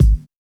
• 2000s Smooth Kick Single Shot G# Key 154.wav
Royality free kick drum one shot tuned to the G# note. Loudest frequency: 168Hz